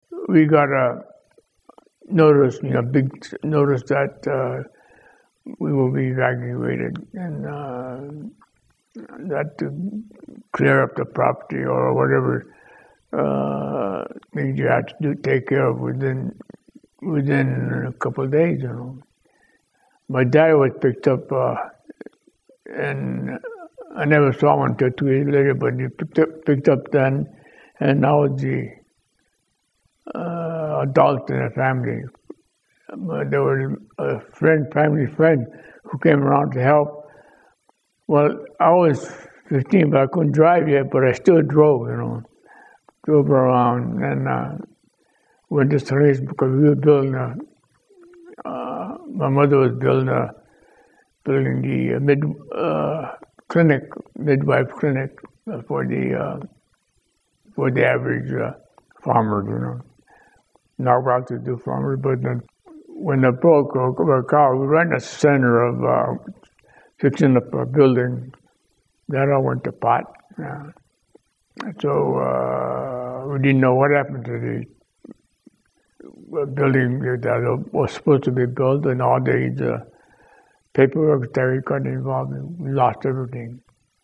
You can also read the TRANSCRIPT of the above interviews.